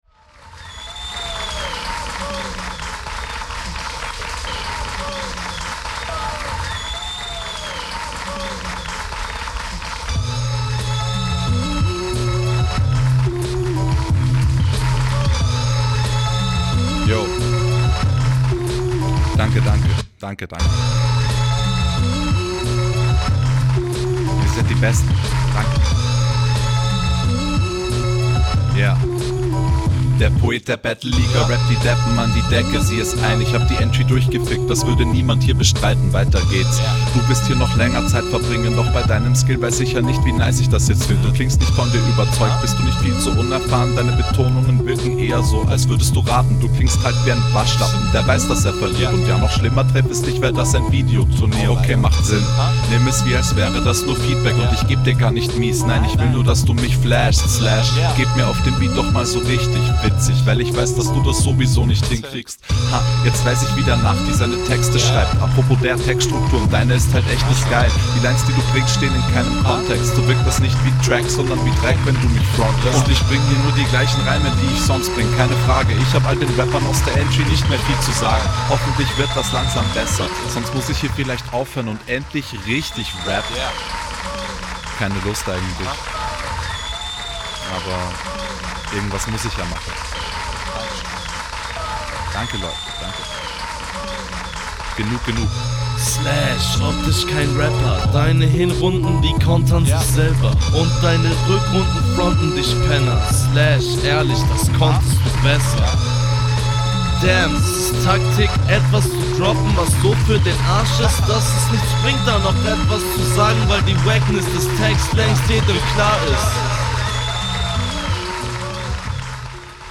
Flow: wie in den runden zuvor sauber und on point jedoch finde ich das insgesamt …